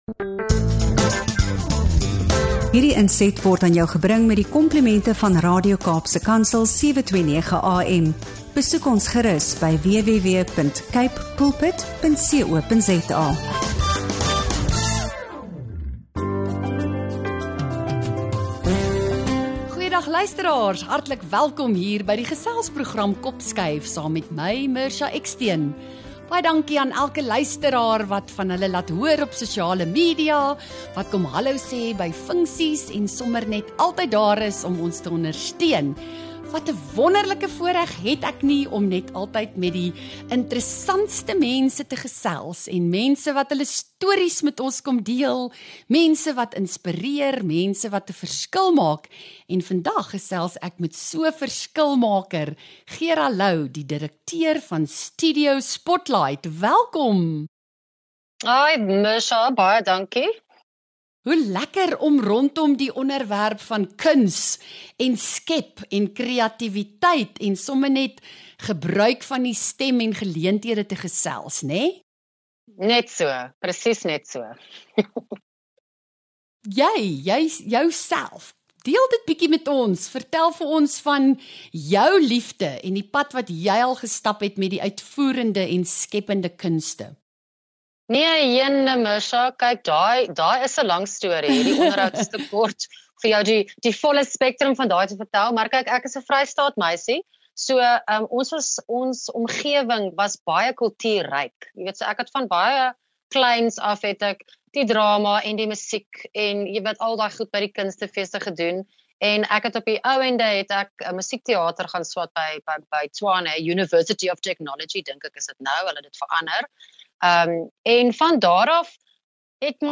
Een van Studio Spotlight se handpoppe deel ook ’n spesiale boodskap.